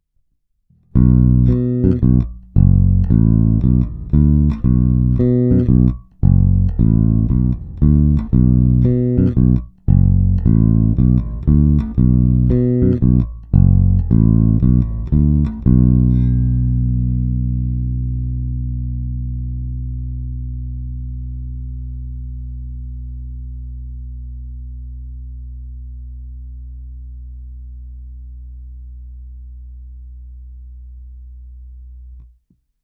Ukázky jsou nahrány rovnou do zvukové karty a jen normalizovány. Hráno vždy nad aktivním snímačem, v případě obou pak mezi nimi.
Oba snímače